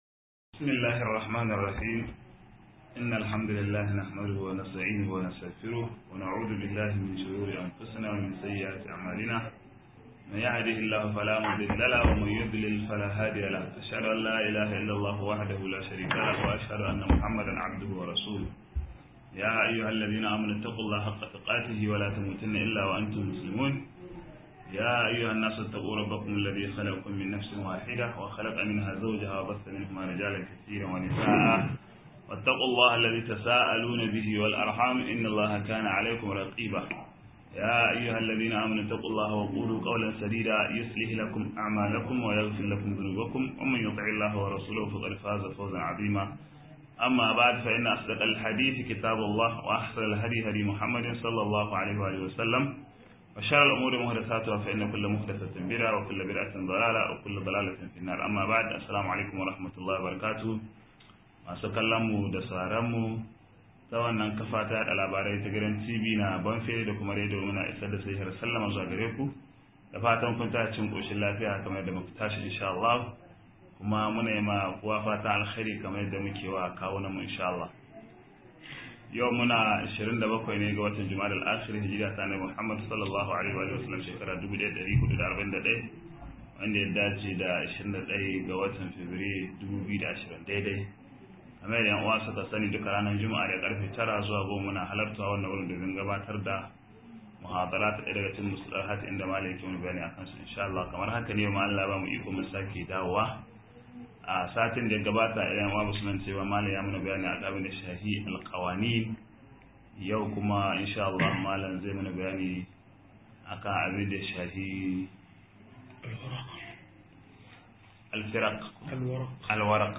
50-Takadda a Musulinci - MUHADARA